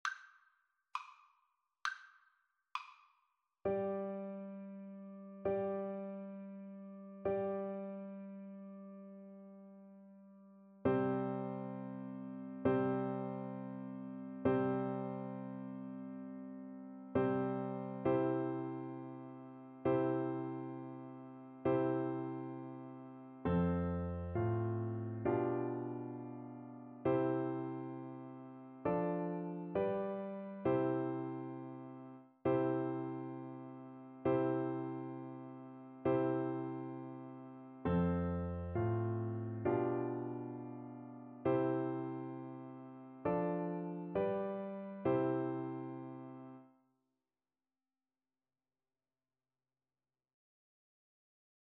Allegretto
6/8 (View more 6/8 Music)
Classical (View more Classical Cello Music)